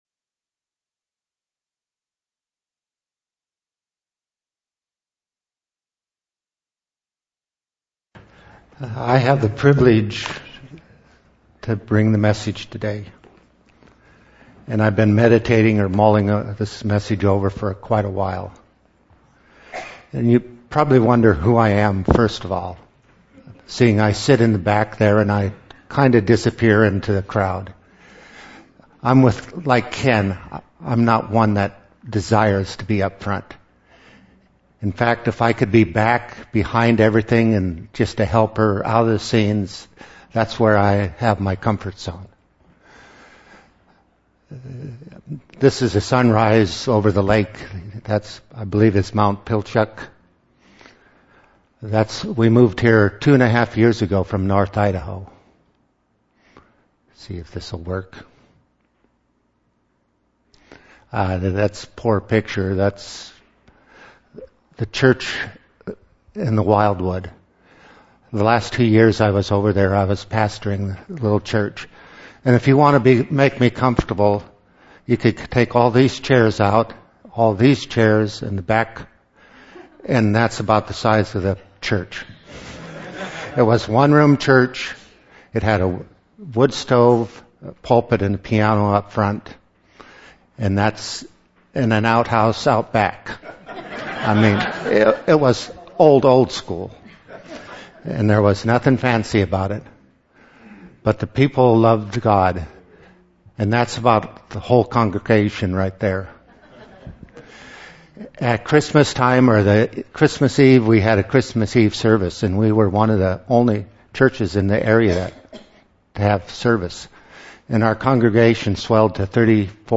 Special Speaker